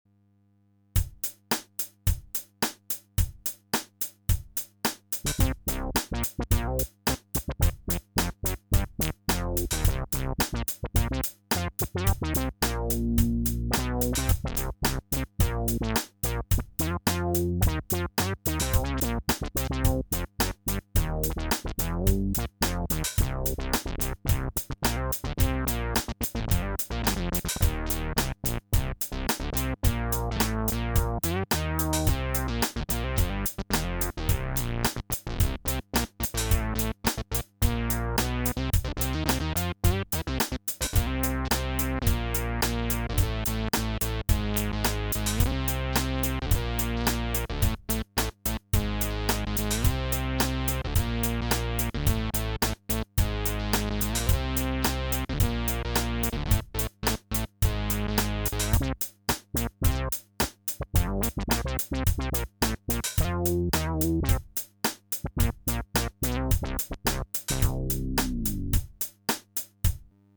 Annoying Buzz – Future Impact Program Database
Annoying+Buzz.mp3